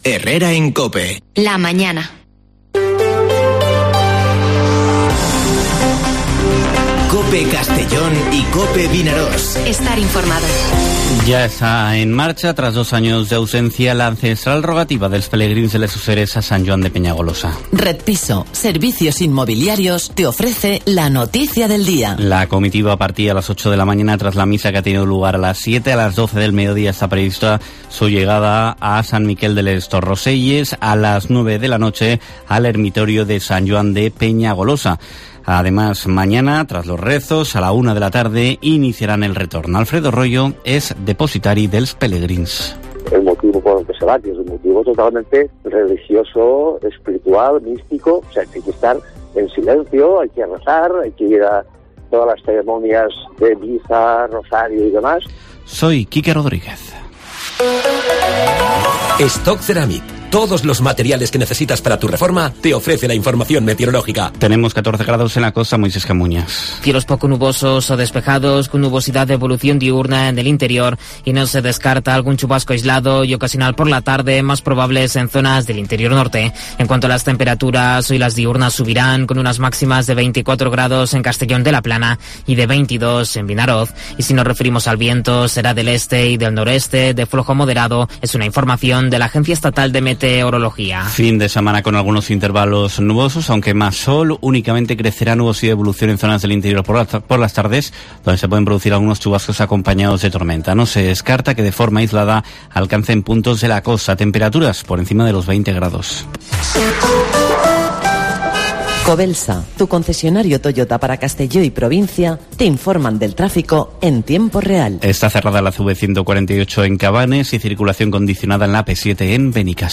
Informativo Herrera en COPE en la provincia de Castellón (29/04/2022)